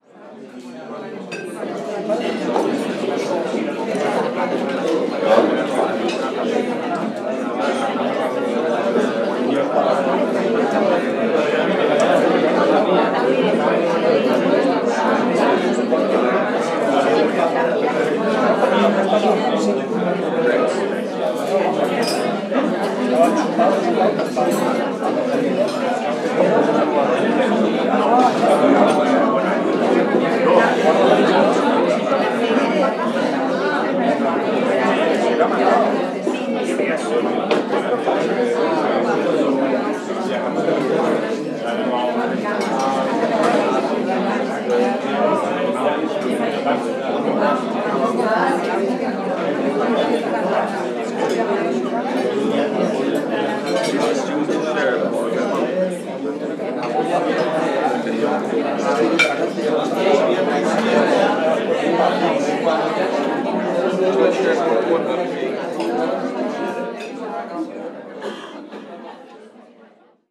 Ambiente de un restaurante español